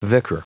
Prononciation du mot vicar en anglais (fichier audio)
Prononciation du mot : vicar